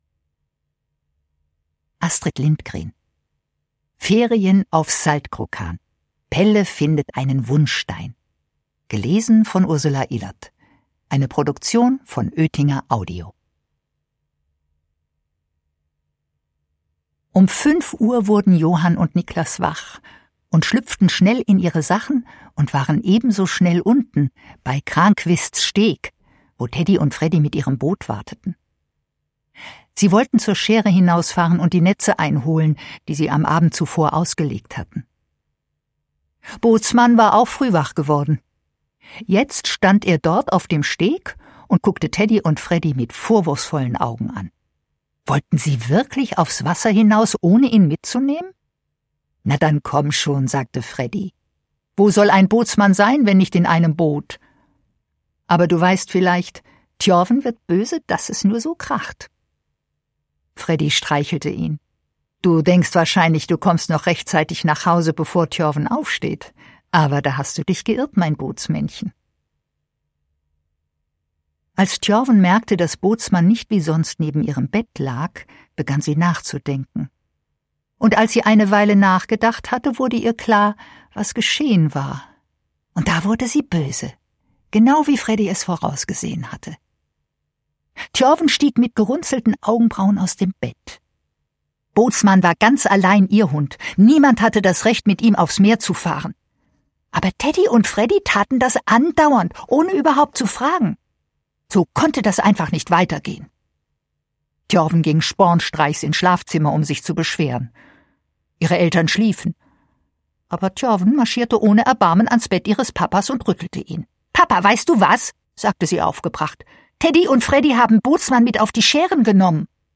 Hörbuch: Ferien auf Saltkrokan.